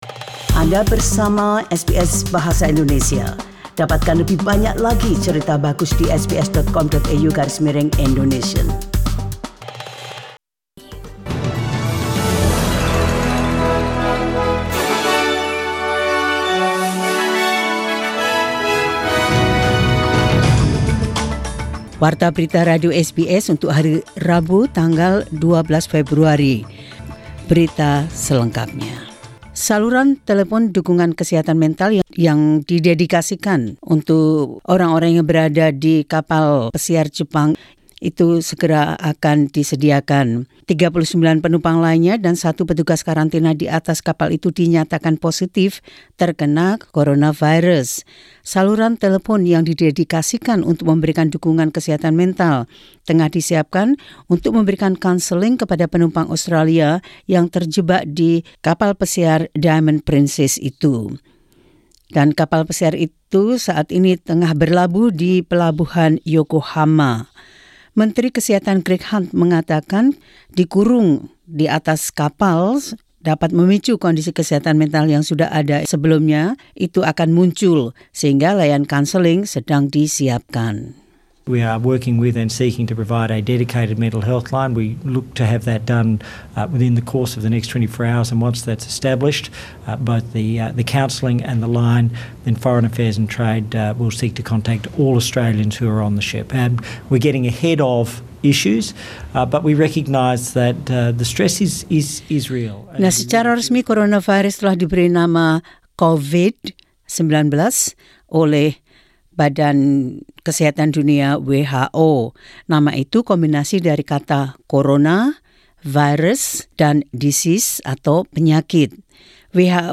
SBS Radio News in Indonesian 12 Feb 2020.